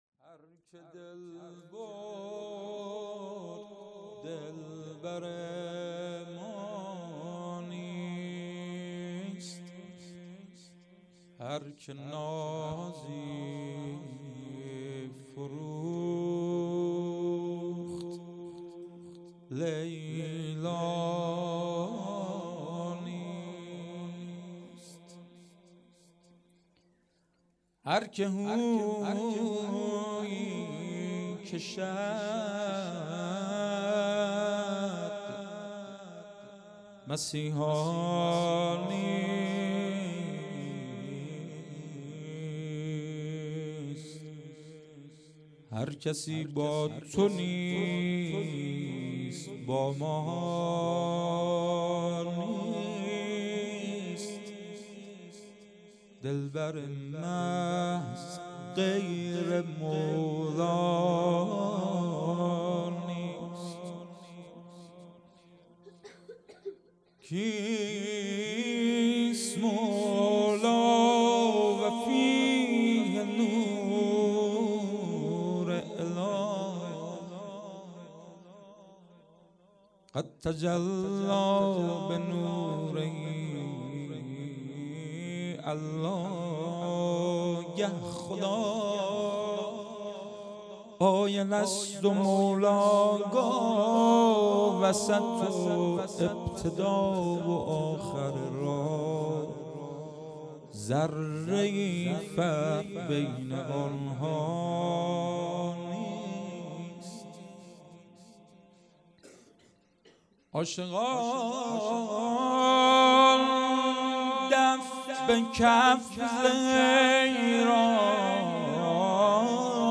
مدح
جشن ولادت امام جواد علیه السلام